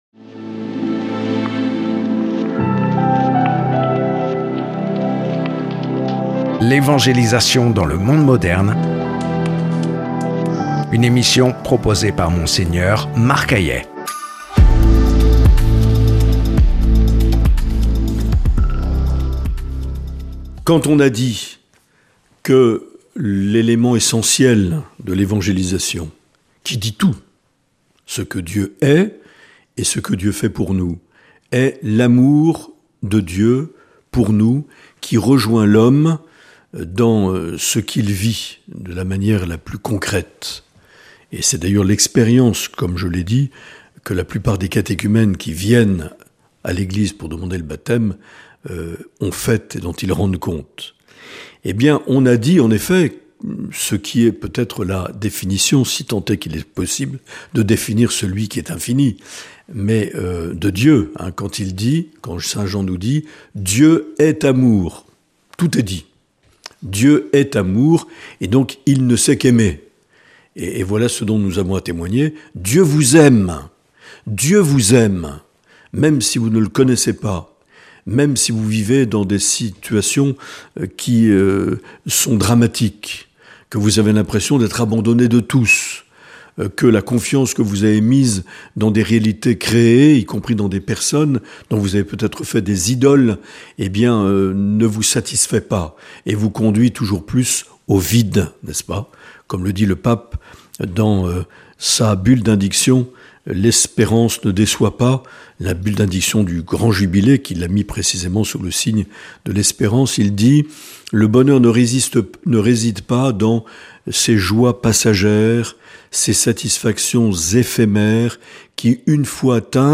Une émission présentée par
Présentateur(trice)